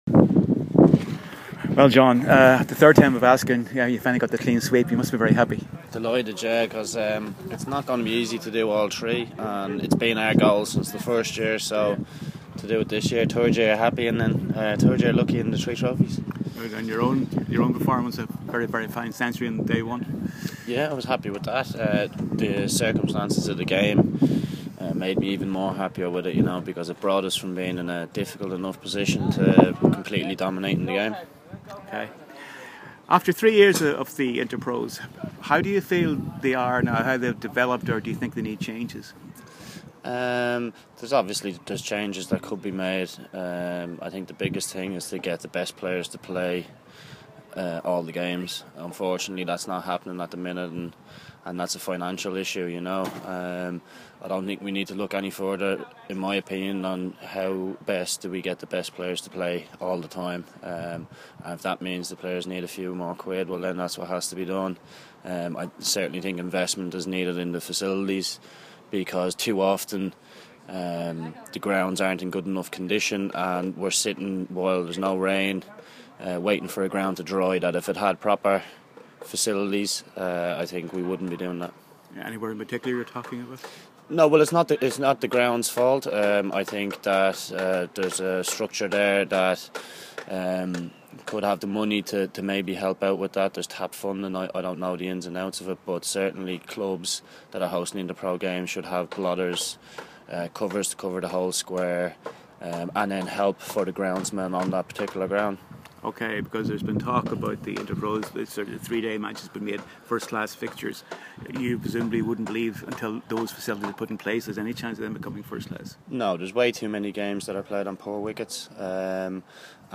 Leinster Lightning v Northenr Knights (8-10 September) Leinster Lightning v Northenr Knights (8-10 September) Interview with the victorious Lightning captain John Mooney (10 September, ©CricketEurope)